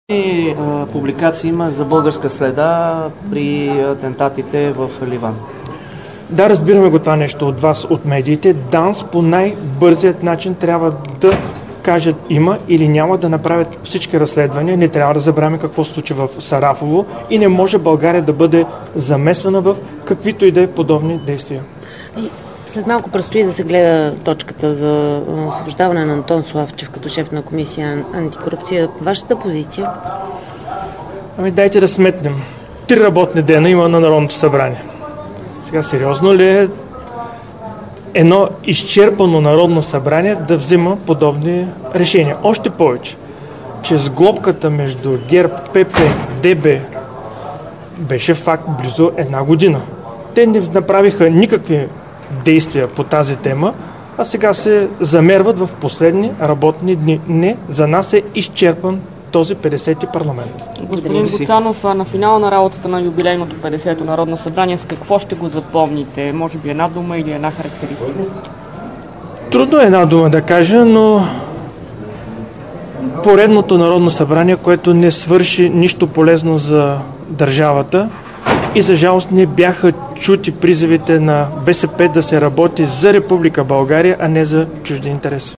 10.05 - Брифинг на председателя на „Възраждане" Костадин Костадинов.  - директно от мястото на събитието (Народното събрание)